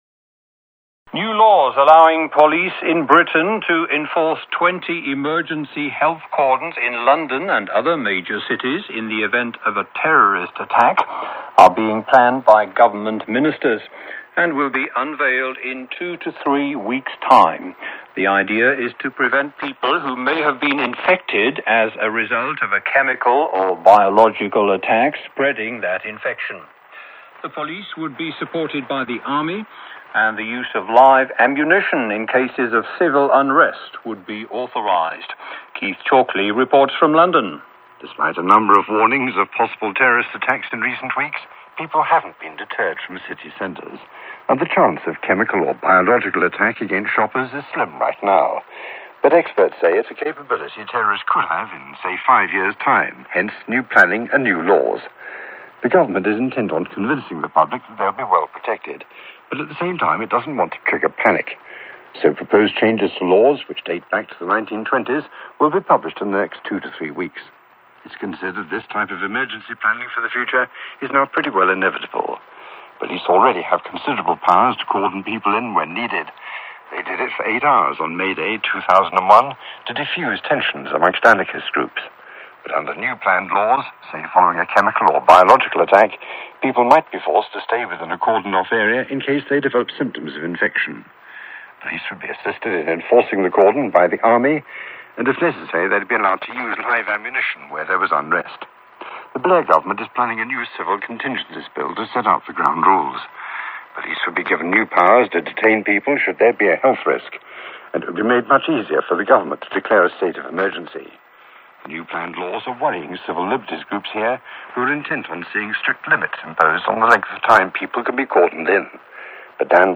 Radio Report from UK, About new police state laws
Mp3 recording for radio broadcast decribing roundup and live ammo use against civilans in the U.K. after a Government sponsored Biological or chemical attack. Talks about live ammo, and sports stadiums, round ups.(Yea its always good to pack people together when an attack happens, I mean if you want them all to die) Wouldn't it be better to stay in your house away from sick people?